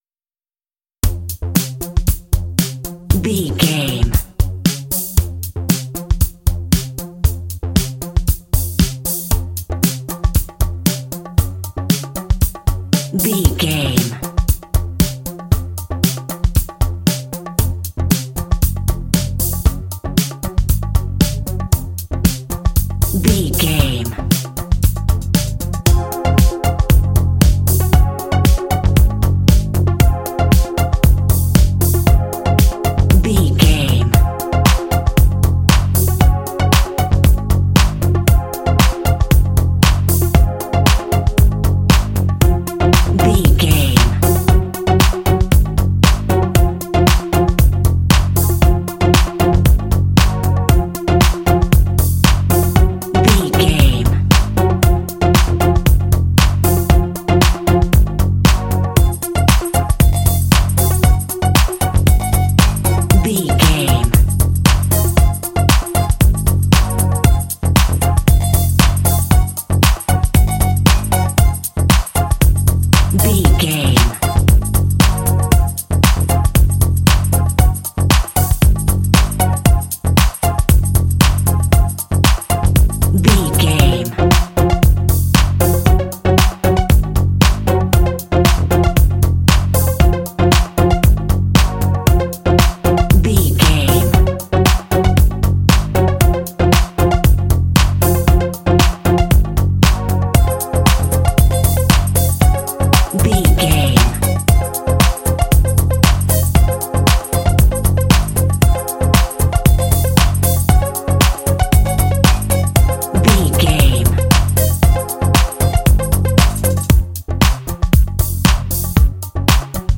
This funky club music track is ideal for adult action games.
Fast paced
In-crescendo
Phrygian
bouncy
groovy
synthesiser
percussion
conga
bass guitar
drums
synth pop